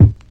07_Kick_10_SP.wav